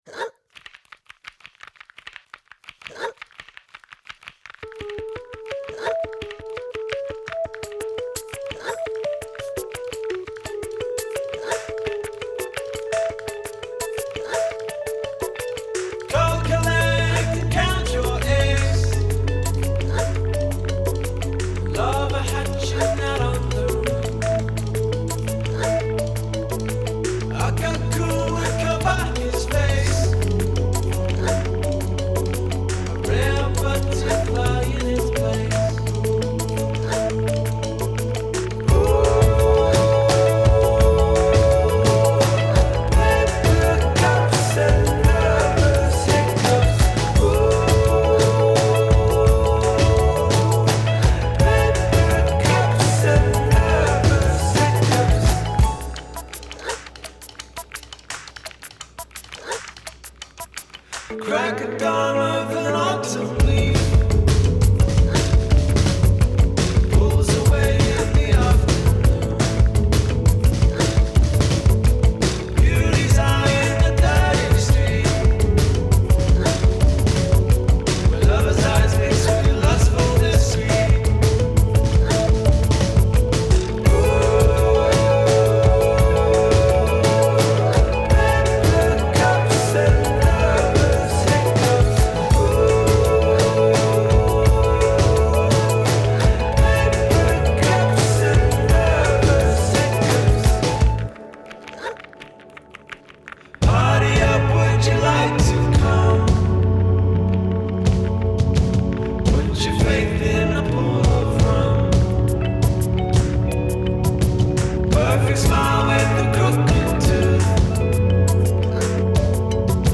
It’s a breezy and adventurous tune